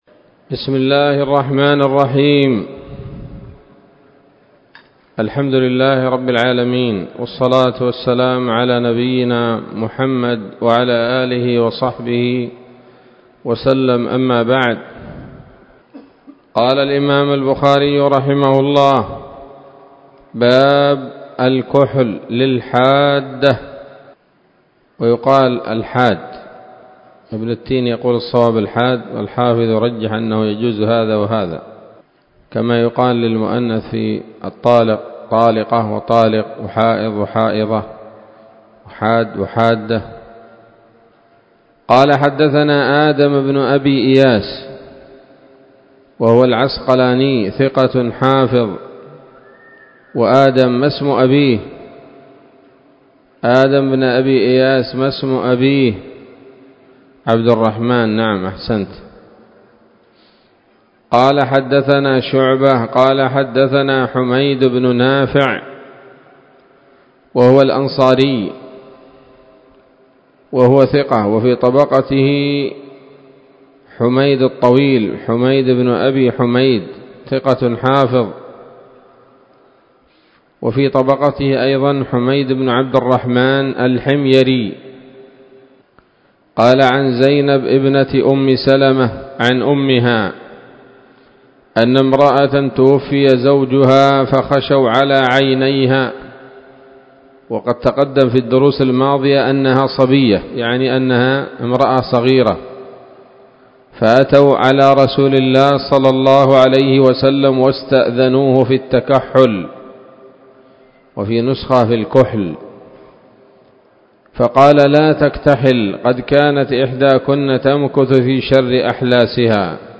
الدرس السادس والثلاثون من كتاب الطلاق من صحيح الإمام البخاري